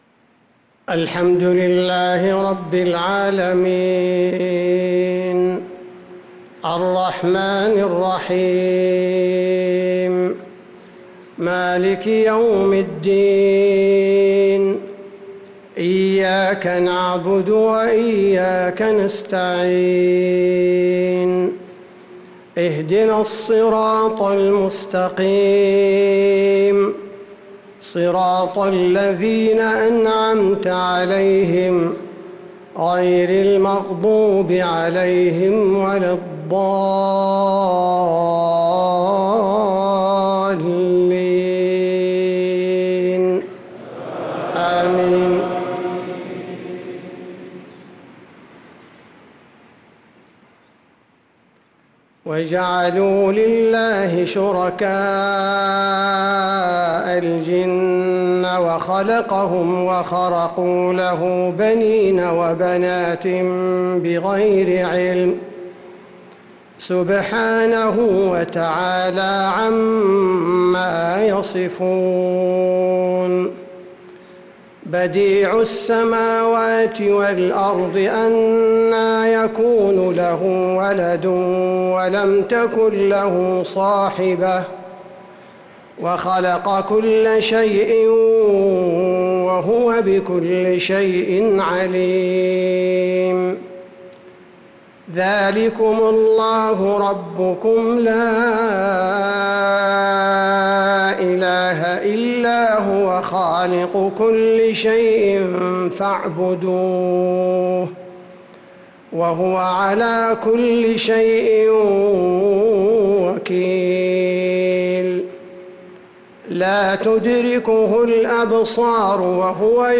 مغرب الأربعاء 1-3-1446هـ من سورة الأنعام | Maghrib Prayer from Surat Al-An'aam 4-9-2024 > 1446 🕌 > الفروض - تلاوات الحرمين